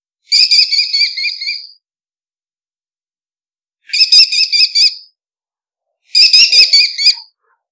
Falco sparverius cinnamominus - Halconcito común
halconcito.wav